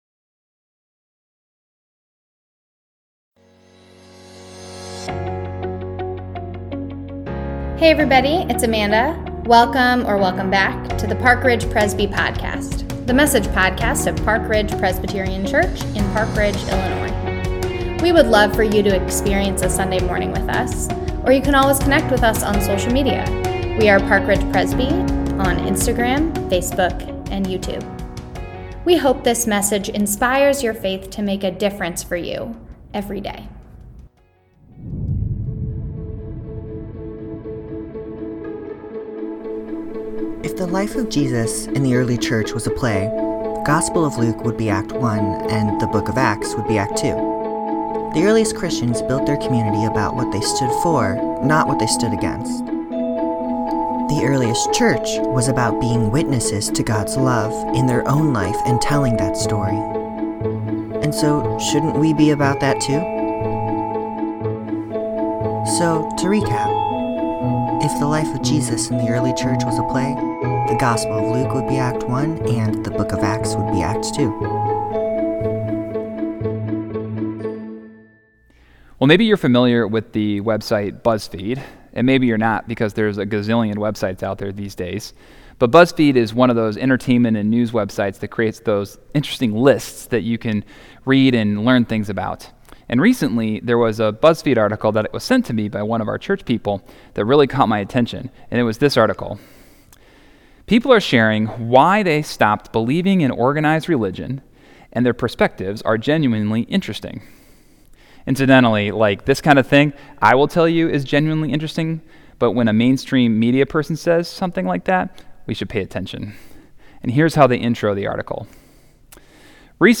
May2_Sermon.mp3